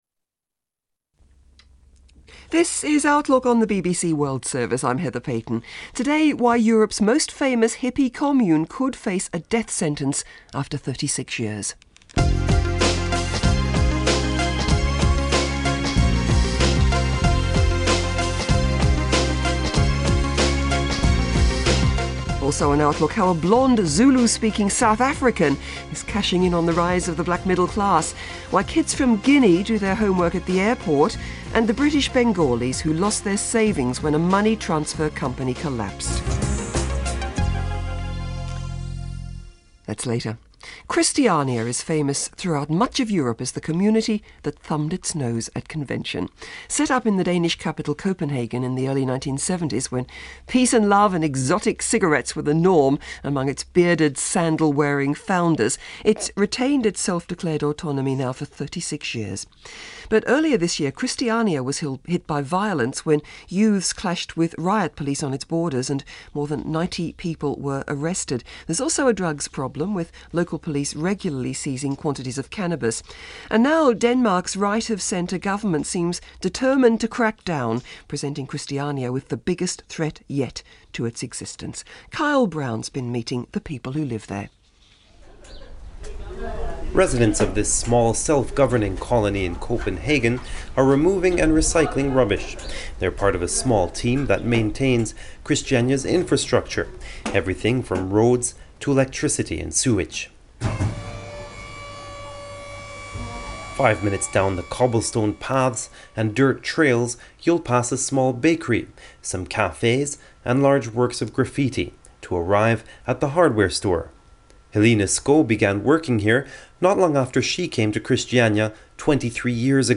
Listen to the report on the BBC World Service:
Christiania-Full-Length-for-BBC-Outlook.mp3